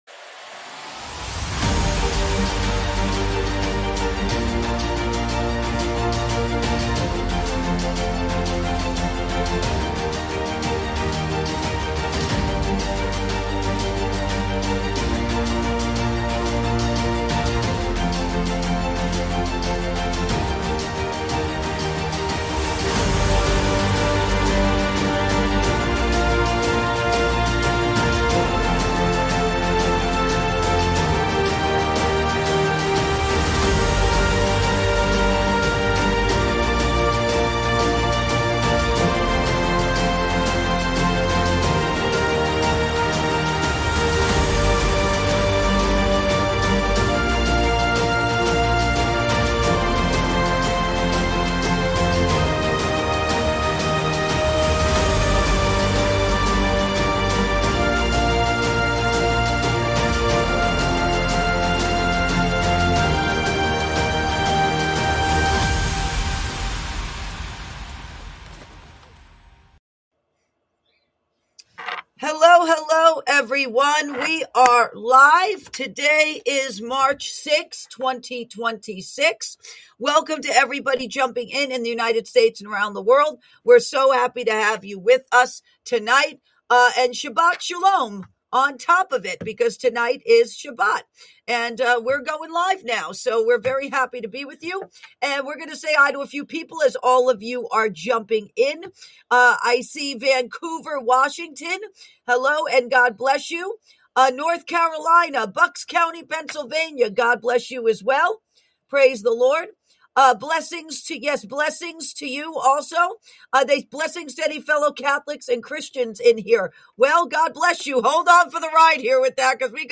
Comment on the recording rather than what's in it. In this live broadcast, she shares prophetic insight on current global events and what may be ahead as both the spiritual and natural realms intensify.